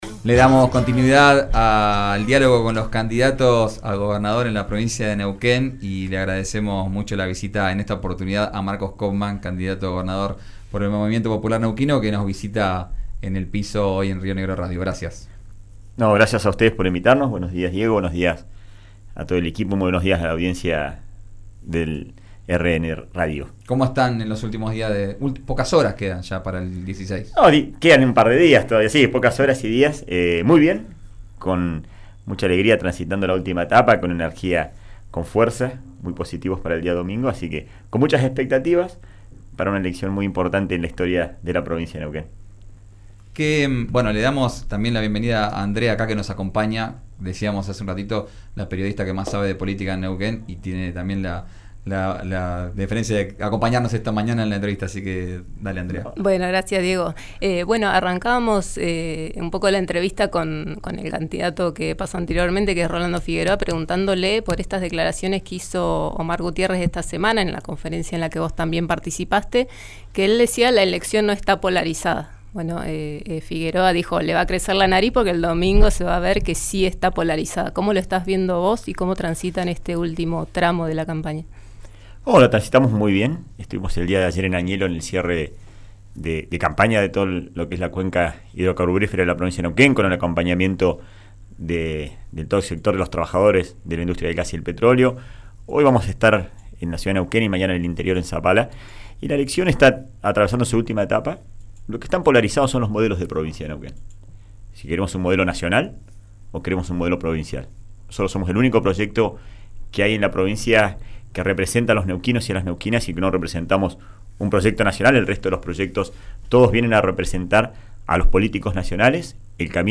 En RÍO NEGRO RADIO contó cómo se viven estas últimas jornadas, respondió los cuestionamientos de Rolando Figueroa y repasó sus propuestas: